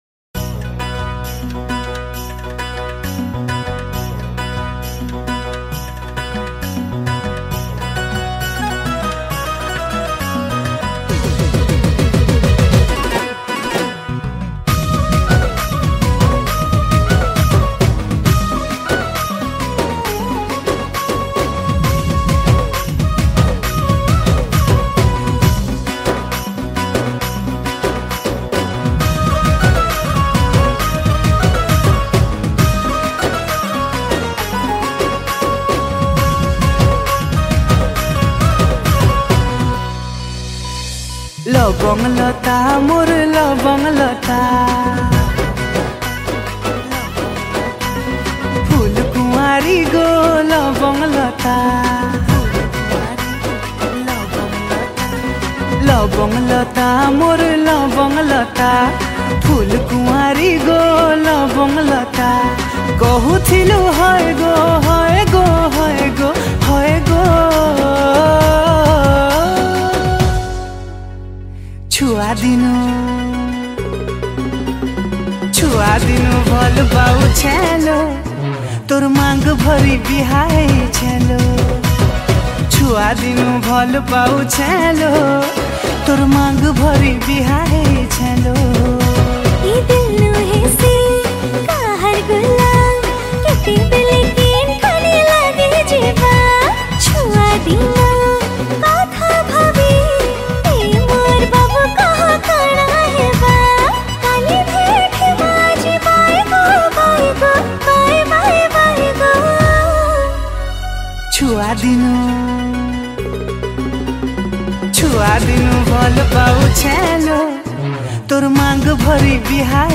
Drum